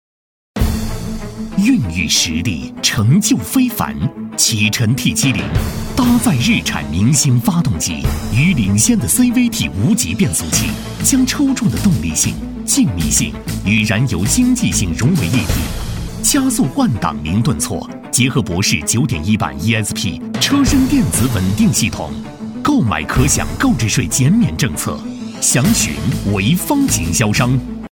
专注高端配音，拒绝ai合成声音，高端真人配音认准传音配音
男58